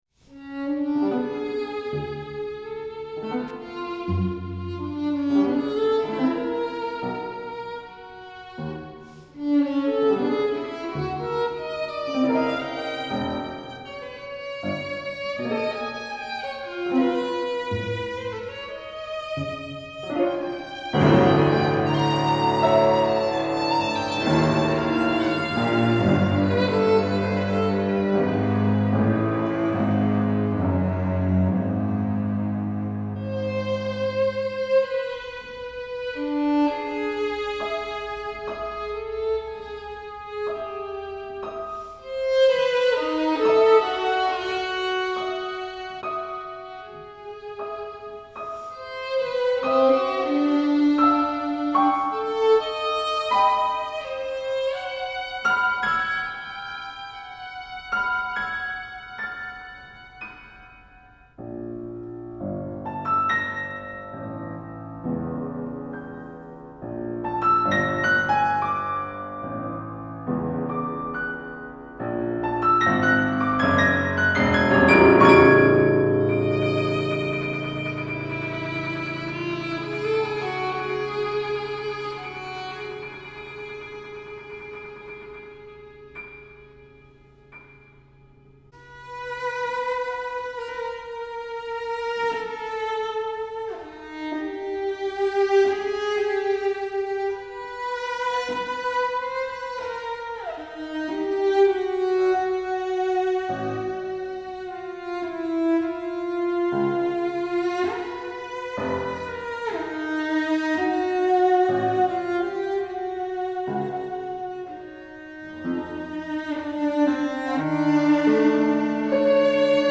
violin, Cello, Piano